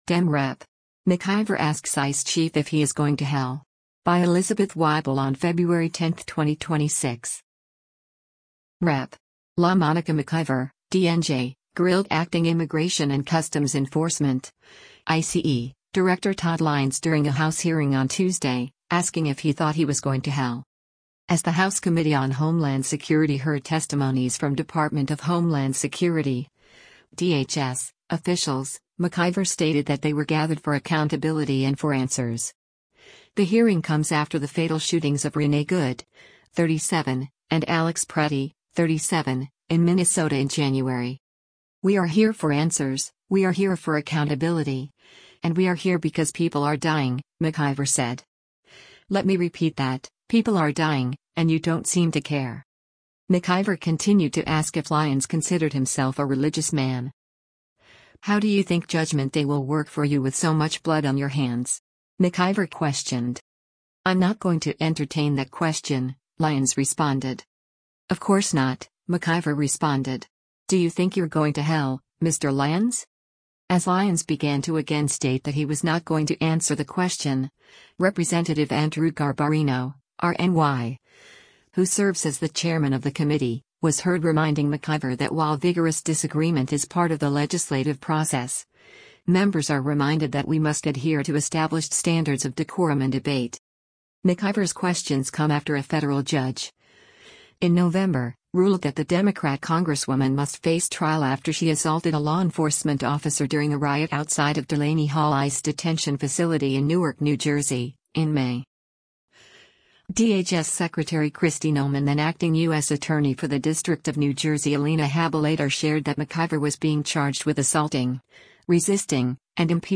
Rep. LaMonica McIver (D-NJ) grilled Acting Immigration and Customs Enforcement (ICE) Director Todd Lyons during a House hearing on Tuesday, asking if he thought he was “going to hell.”
As Lyons began to again state that he was not going to answer the question, Rep. Andrew Garbarino (R-NY), who serves as the Chairman of the committee, was heard reminding McIver that “while vigorous disagreement is part of the legislative process, members are reminded that we must adhere to established standards of decorum and debate.”